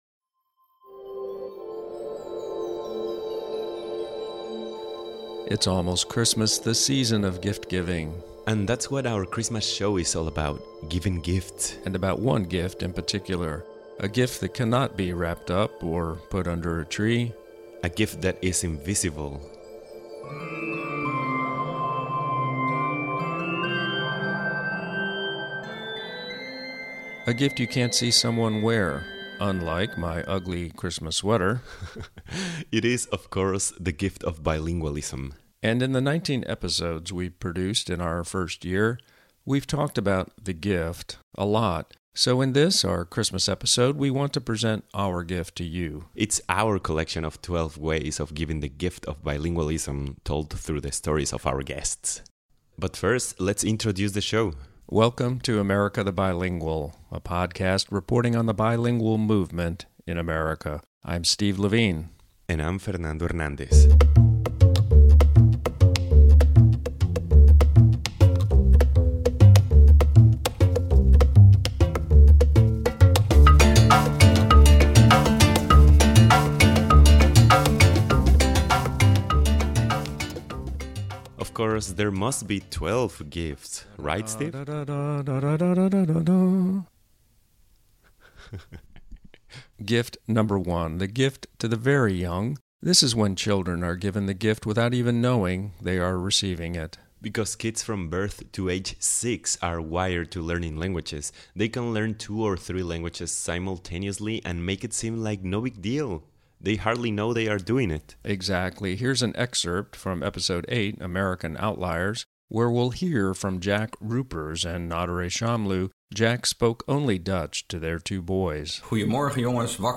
Our gift to you this Christmas season is a collection of 12 ways to experience this gift . We’re featuring excerpts from some of our favorite podcast moments of the year.